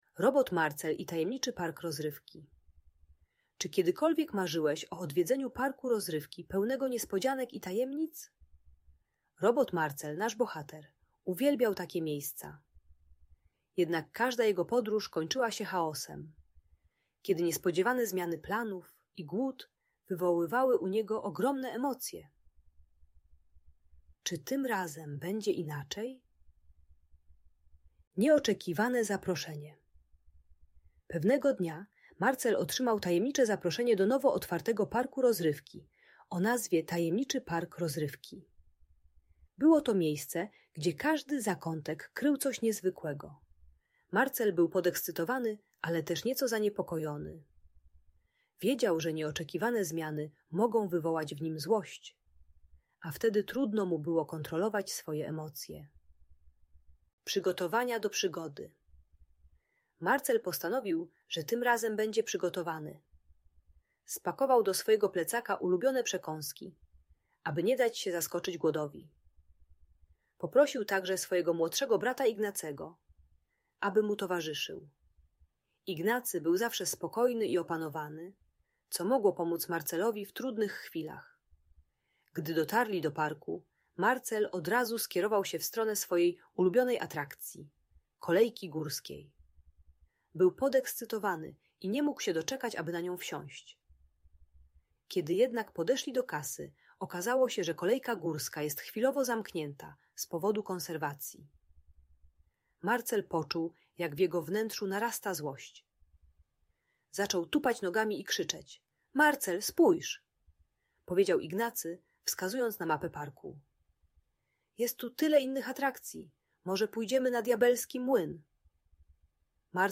Robot Marcel i Tajemniczy Park Rozrywki - Agresja do rodziców | Audiobajka